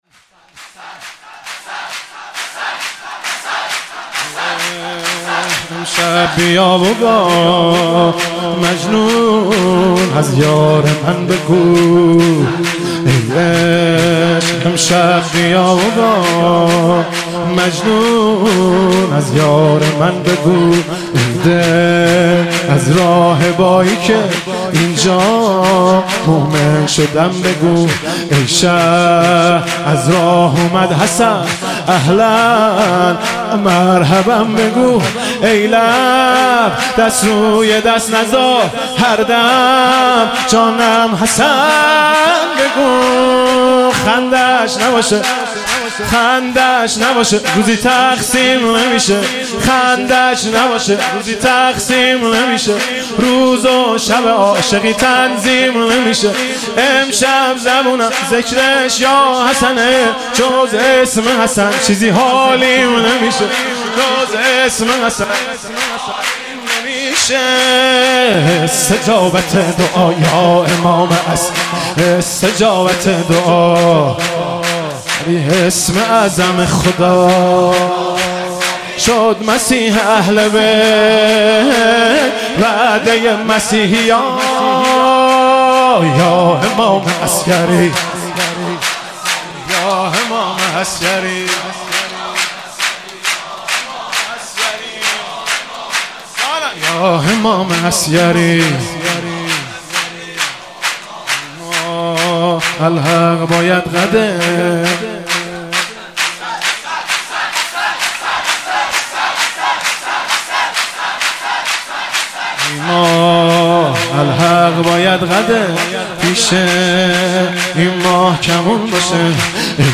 با صدای دلنشین
با نوای دلنشین